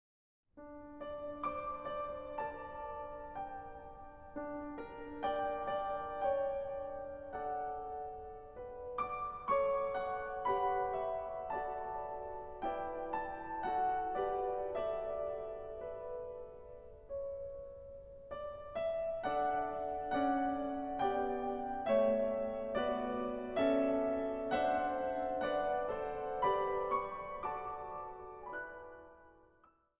Zang | Gemengd koor